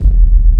41 BASS02 -L.wav